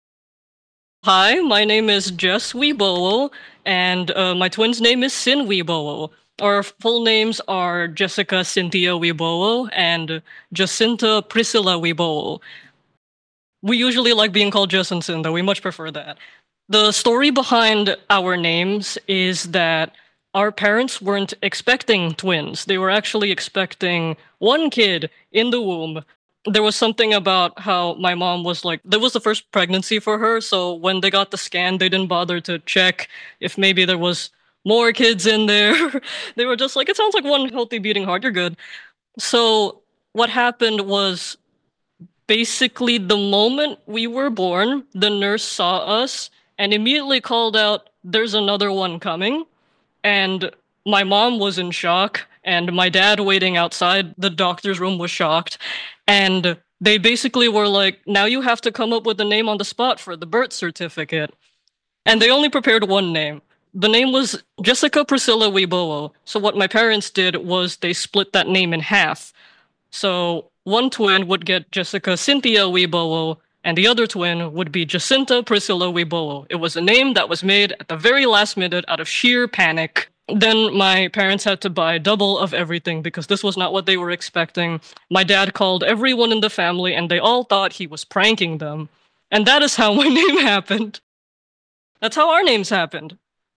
Audio Name Pronunciation
Educator Note: This primary source recording gives insight into a book creator and is not directly tied to a specific book.